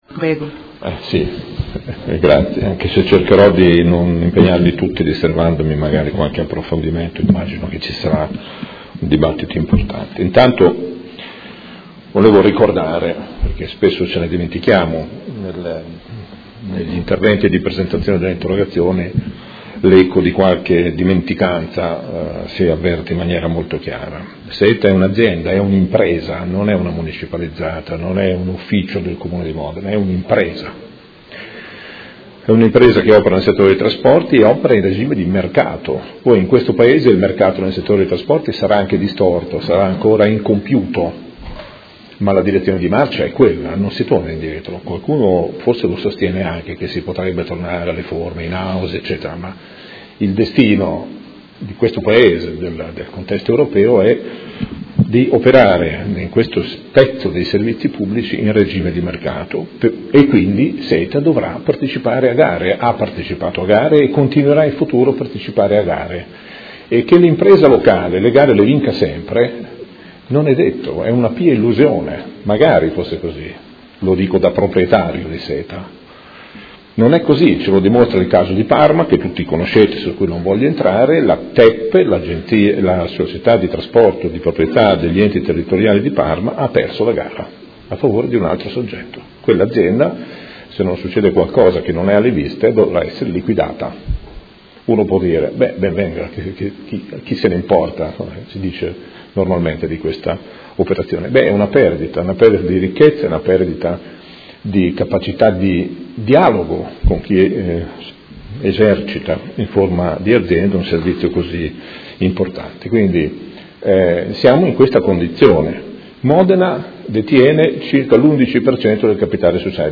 Seduta del 27/04/2017 Risponde in maniera congiunta a: Interrogazione del Gruppo Movimento cinque Stelle avente per oggetto: SETA, continue controversie; Interrogazione del Consigliere Galli (FI) avente per oggetto: Sciopero a Modena degli autisti SETA; davanti ad un’adesione dei lavoratori che ha superato in città il 90%, il management dell’Azienda non si fa qualche domanda?